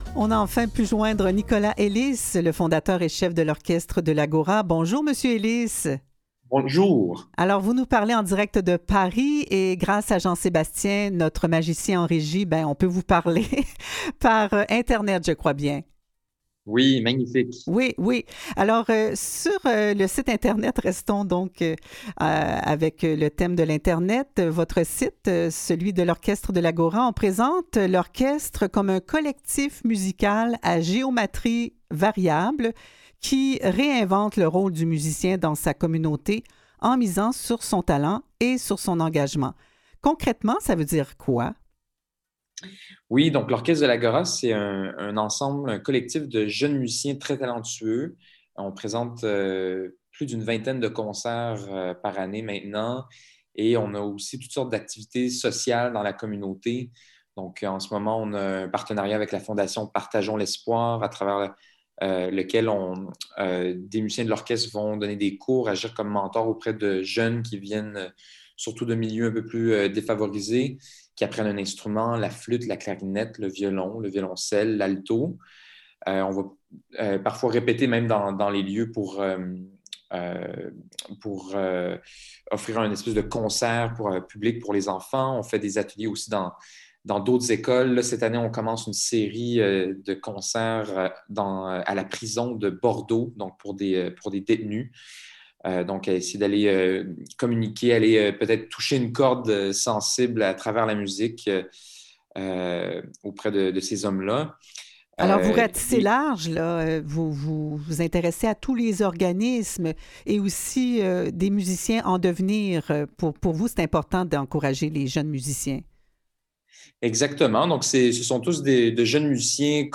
Aux Quotidiens Revue de presse et entrevues du 30 septembre 2021